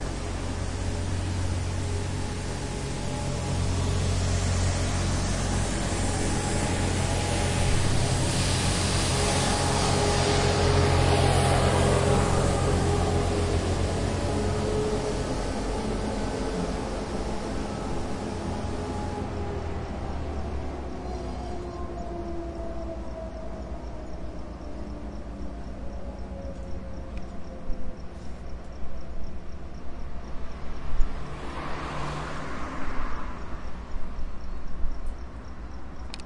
扫路车 " 扫路车原创回顾
描述：记录了tascam dr07mkII，同时在四楼的indow上抽烟。
标签： 背景音 ATMOS 声景 氛围 环境 现场记录 一般噪声 环境 夜间 工业 字段 清扫车 噪声 大气 城市
声道立体声